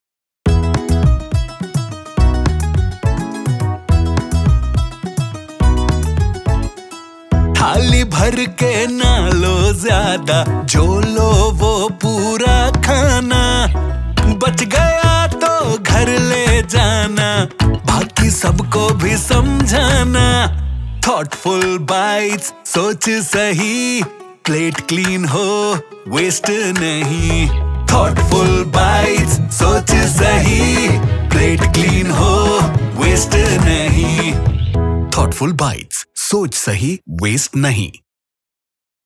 Audio Jingle